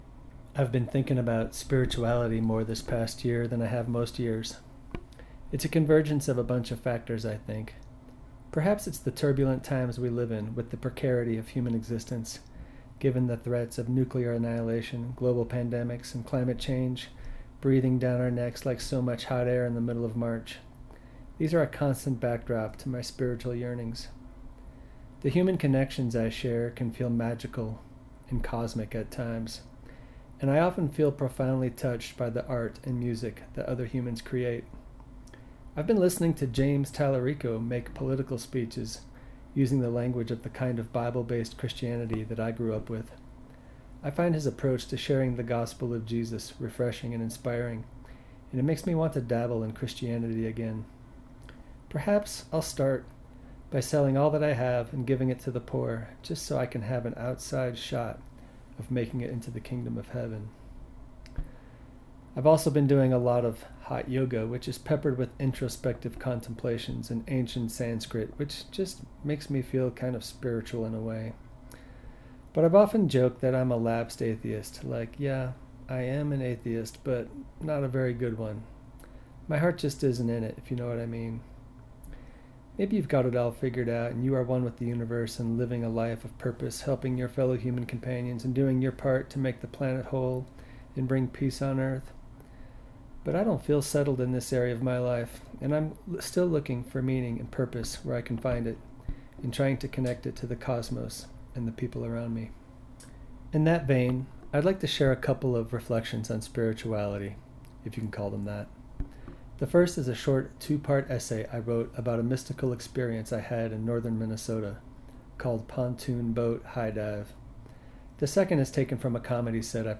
The second is a taken from a comedy set I performed on my 50th birthday where I contemplated what I love about